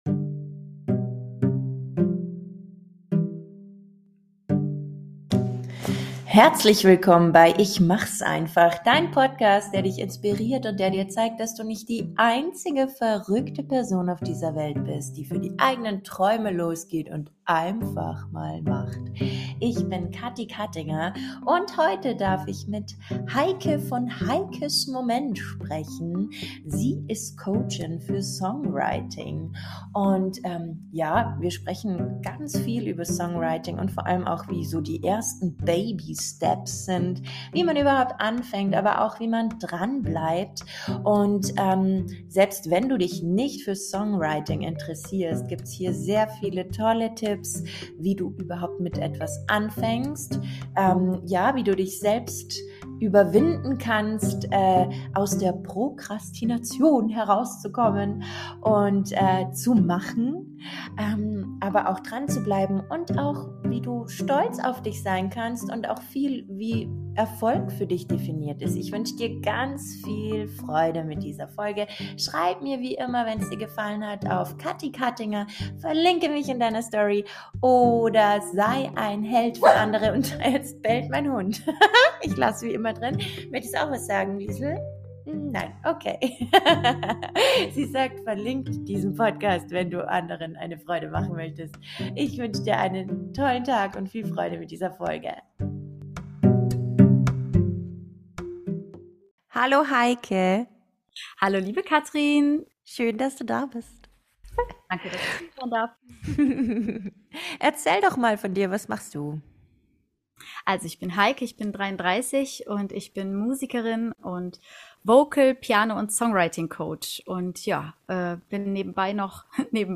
Im Interview spreche ich mit ihr darüber, wie man überhaupt beginnt eigene Lieder zu schreiben, wie man dran bleibt und wie man lernt auf sich selbst und die eigene Kunst stolz zu sein. Vielleicht inspiriert Dich diese Folge dazu, Deine eigene Kunst zu beginnen.